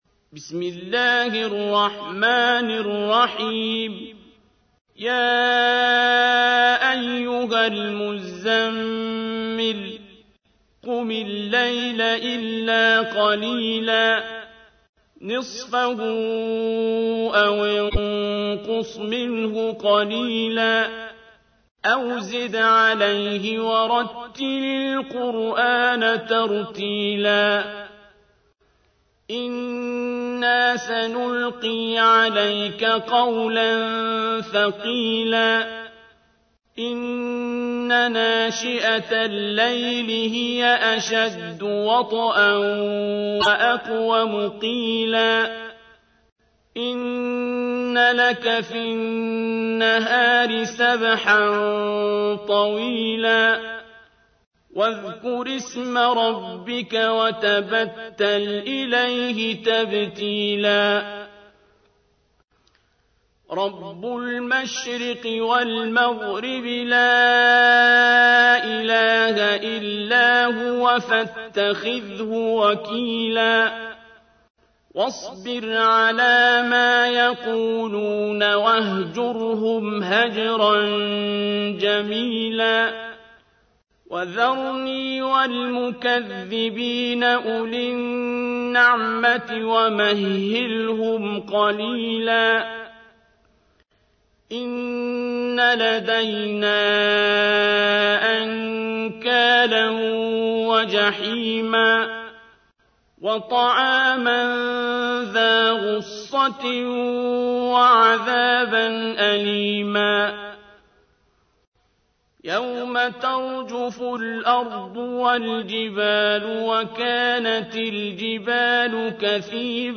تحميل : 73. سورة المزمل / القارئ عبد الباسط عبد الصمد / القرآن الكريم / موقع يا حسين